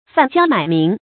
贩交买名 fàn jiāo mǎi míng
贩交买名发音
成语注音ㄈㄢˋ ㄐㄧㄠ ㄇㄞˇ ㄇㄧㄥˊ